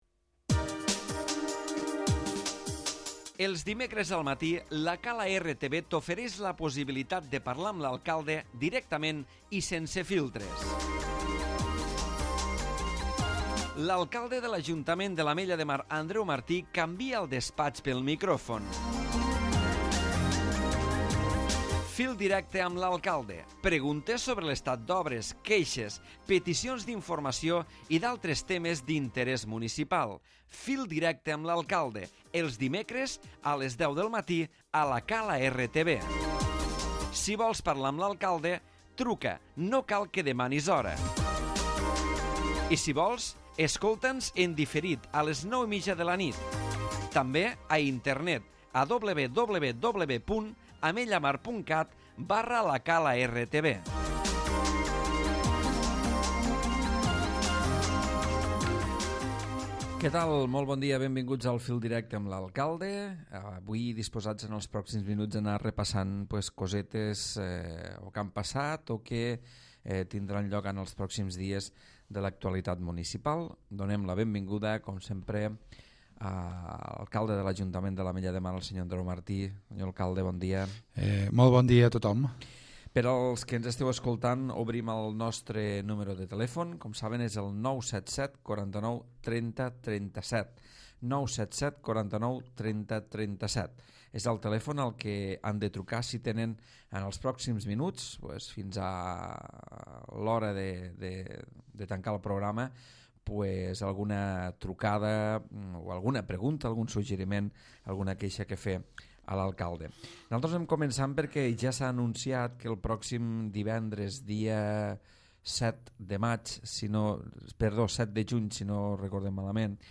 Andreu Martí ha atès les trucades dels ciutadans al programa Fil Directe amb l'Alcalde on també ha parlat de l'acte d'entrega dels Ebreliders, de les Banderes blaves, de la nova App per a mòbils i de les reivindicacions de gratuïtat de l'AP7.